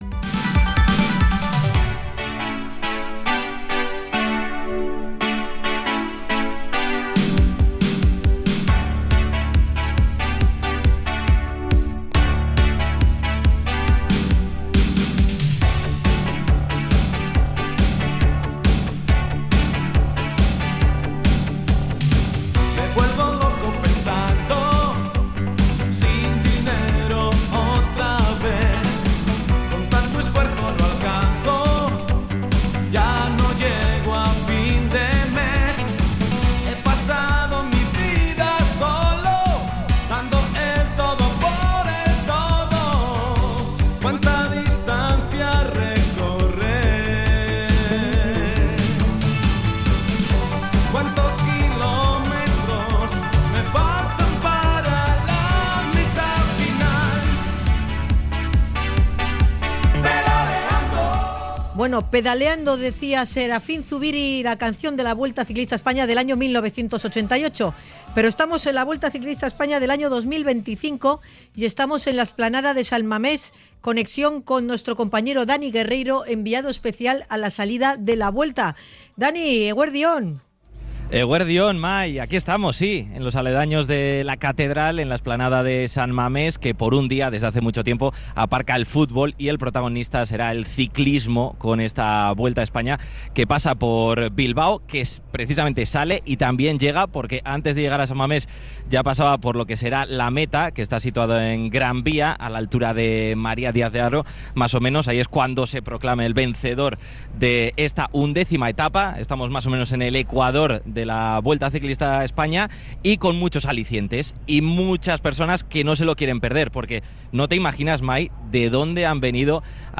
Salimos a la calle para comprobar la gran expectación de curiosos en torno al despliegue de medios de la Vuelta